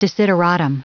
Prononciation du mot desideratum en anglais (fichier audio)
Prononciation du mot : desideratum